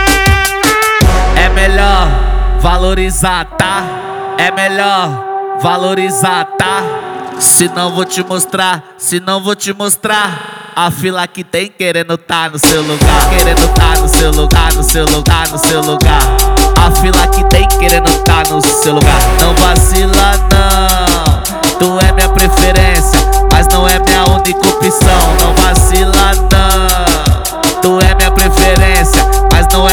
# Brazilian